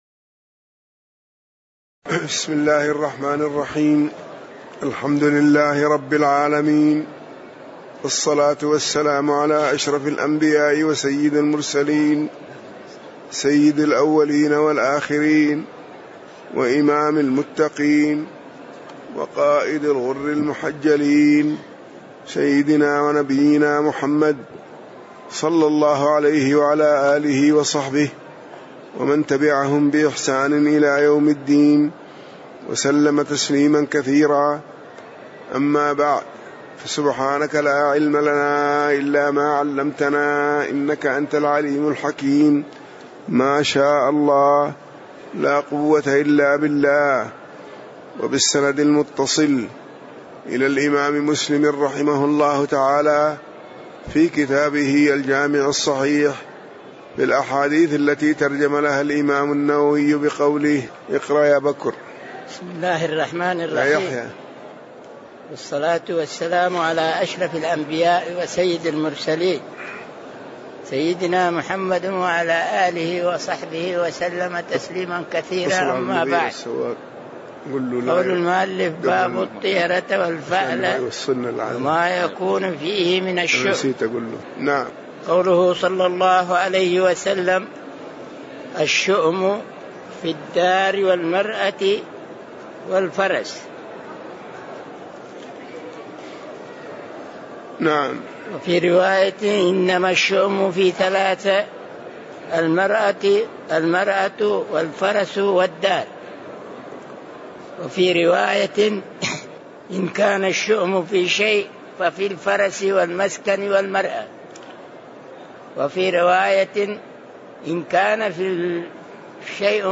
تاريخ النشر ٢٧ صفر ١٤٣٧ هـ المكان: المسجد النبوي الشيخ